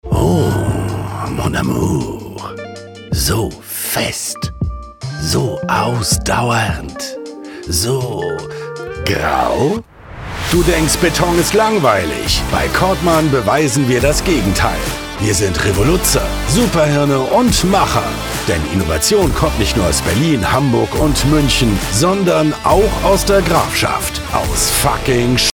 Friendly
Trustworthy
Competent